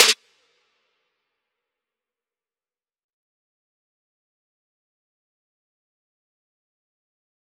DMV3_Snare 16.wav